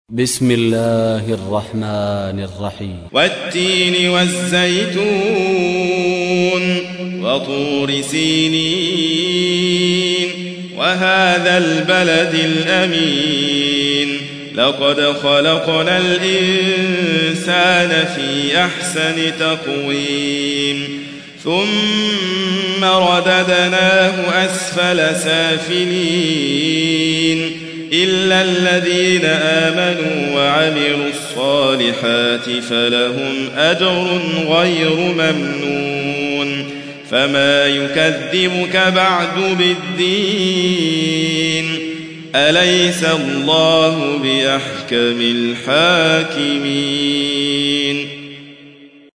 95. سورة التين / القارئ